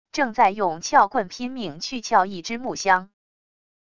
正在用撬棍拼命去撬一只木箱wav音频